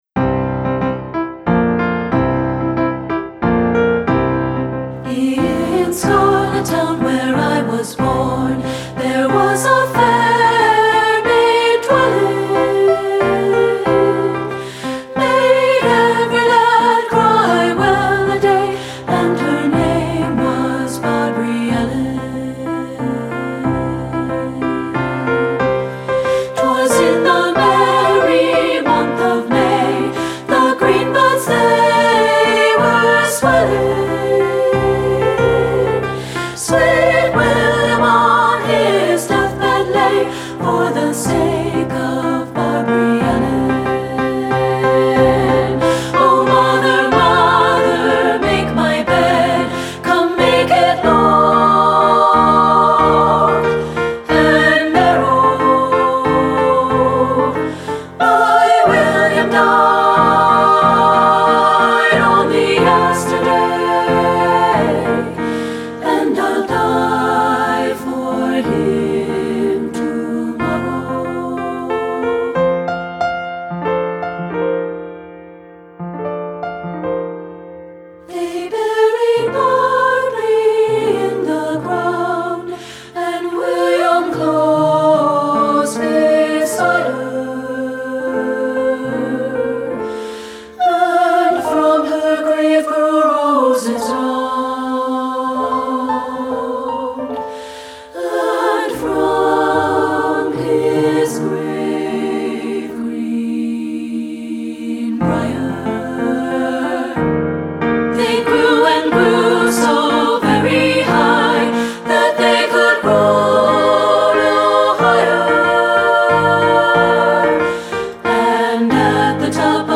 • Soprano 1
• Soprano 2
• Alto
• Piano
Studio Recording
Ensemble: Treble Chorus
Key: F major
Accompanied: Accompanied Chorus